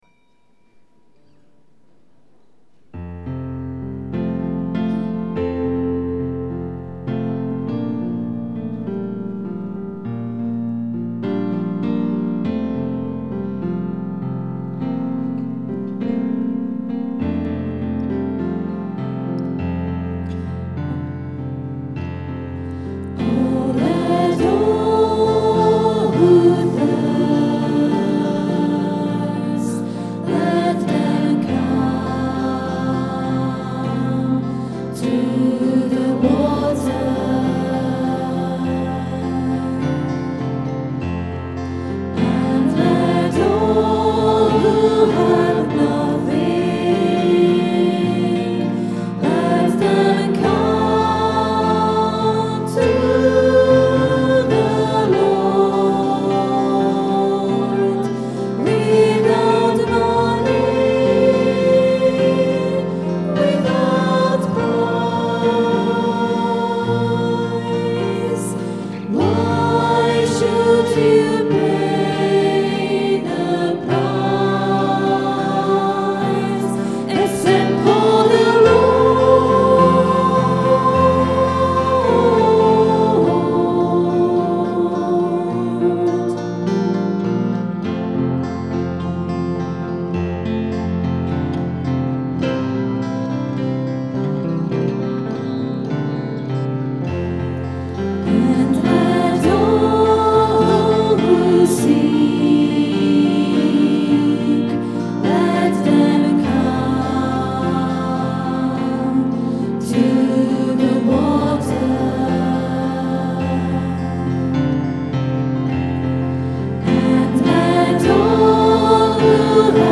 Another haunting and lilting tune. This song was recorded at 10am Mass on Sunday 6th July 2008. Recorded on the Zoom H4 digital stereo recorder through a Behringer SL2442FX mixer. Sound editing and effects using Acoustica Mixcraft 4 audio processing software.